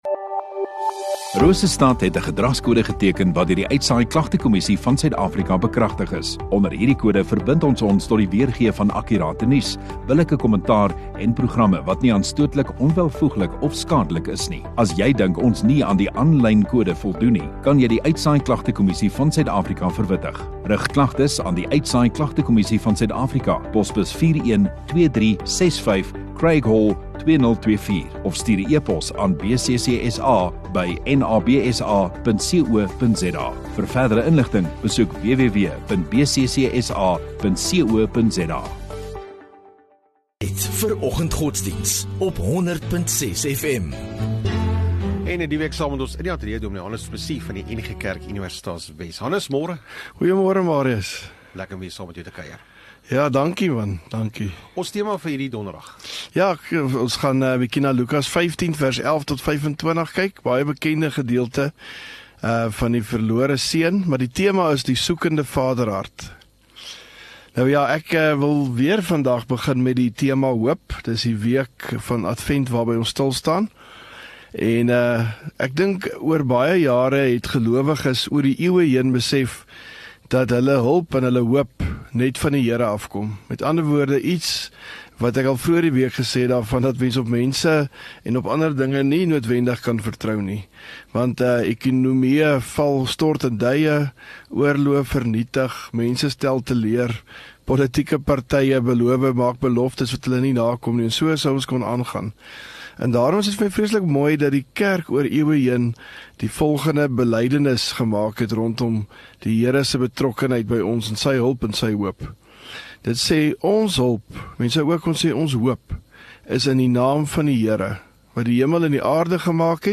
5 Dec Donderdag Oggenddiens